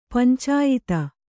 ♪ pancāyita